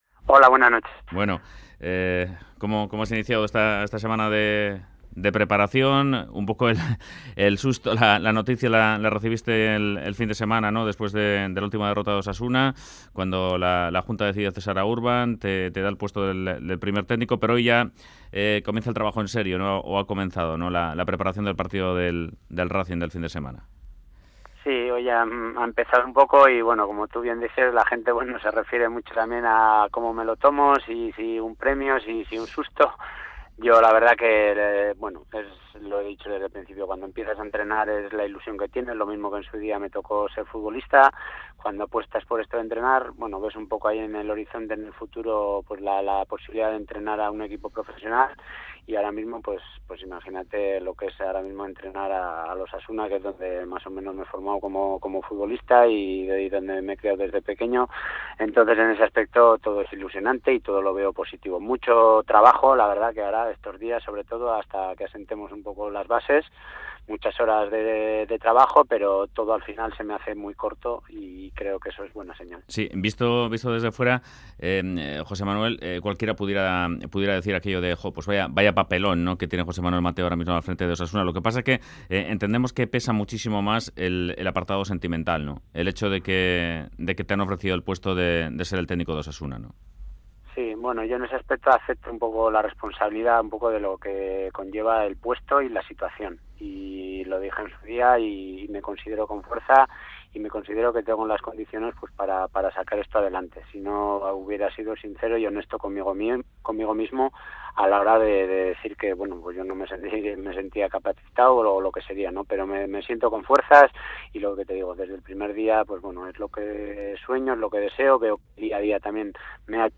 Se pasa por Fuera de Juego de radio euskadi para hablar de su nuevo cargo en el club de su vida.